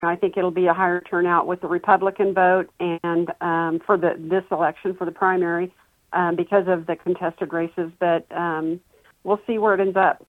Lyon County Election Officer Tammy Vopat spoke with KVOE News Friday and says the slow turnout could be the result of a few factors including this being a primary election with the majority of races including only Republican candidates.